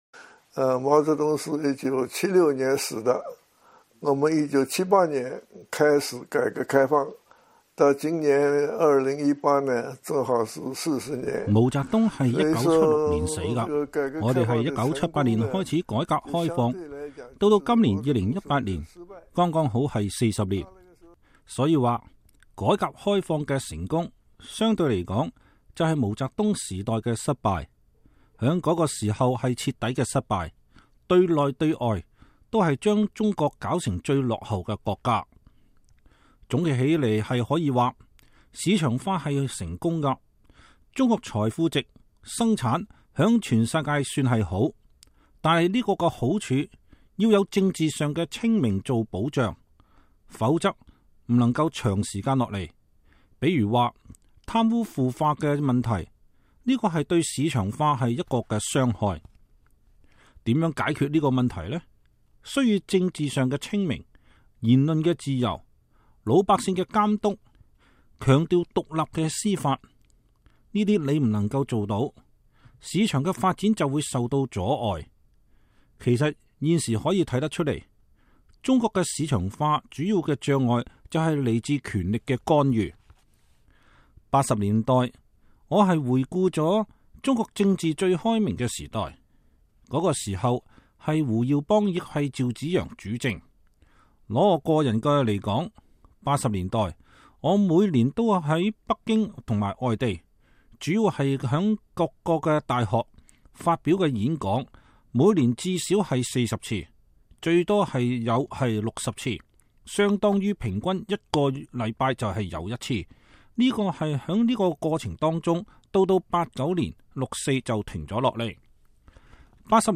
2018年是中國改革開放40週年。美國之音近期訪問了直接參與見證了這場歷史性變革的自由派經濟學者茅于軾等人。